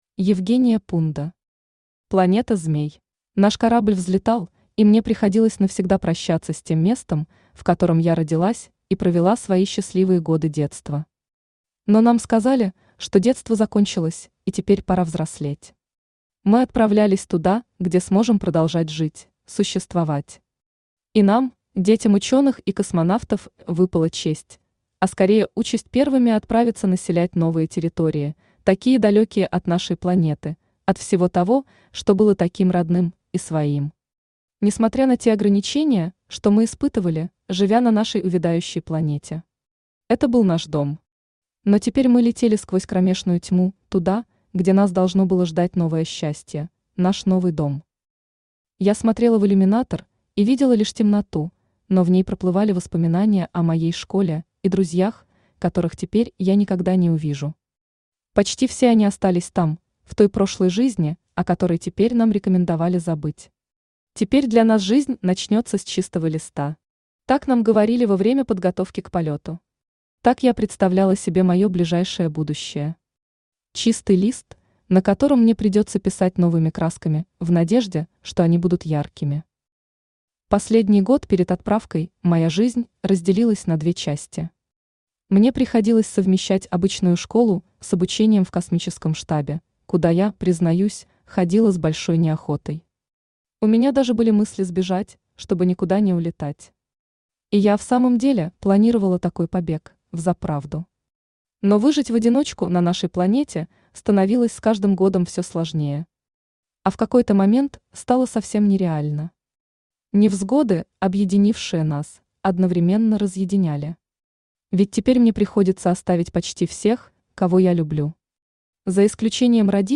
Aудиокнига Планета змей Автор Евгения Пунда Читает аудиокнигу Авточтец ЛитРес.